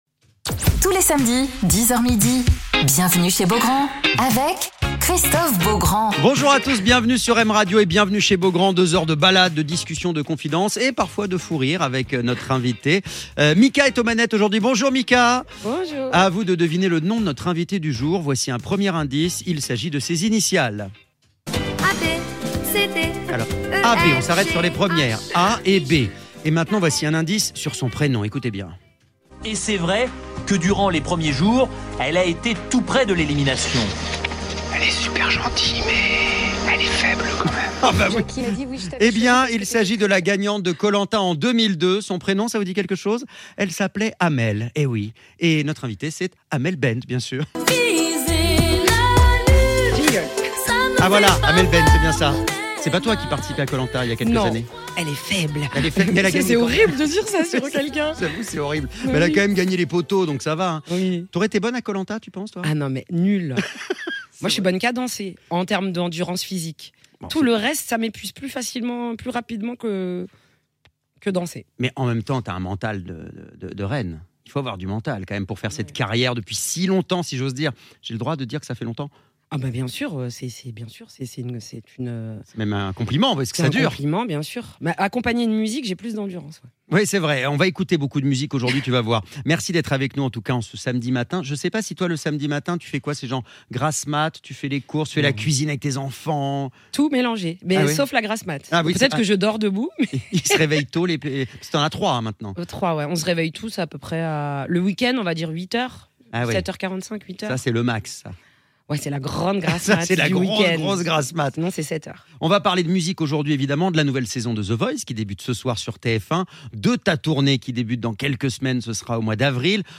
Alors qu'elle prépare une grande tournée partout en France à partir du mois d'avril, Amel Bent est l'invitée de Christophe Beaugrand sur M Radio !